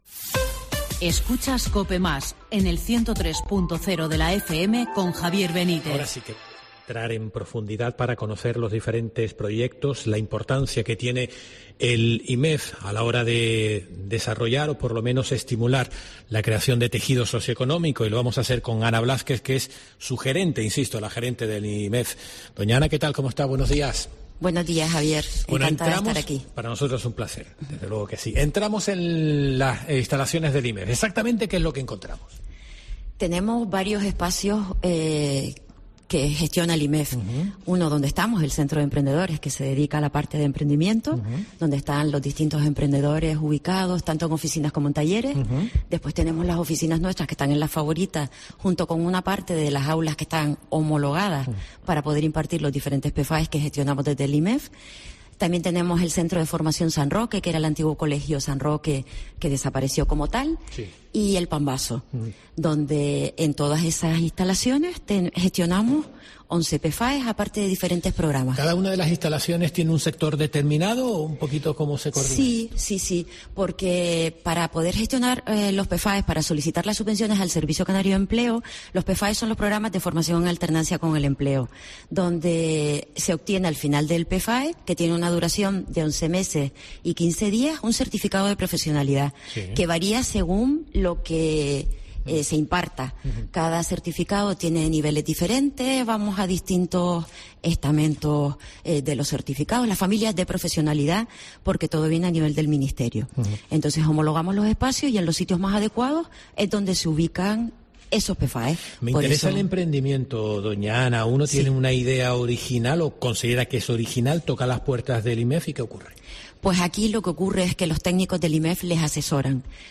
La Mañana de COPE Gran Canaria se ha desplazado hasta el Insituto Municipal de Empleo para conocer los proyectos que se están desarrollando desde el IMEF con el objetivo de luchar contra el desempleo así como lograr la inserción laboral de aquellos que participan en los diferentes programas que la entidad pone en marcha.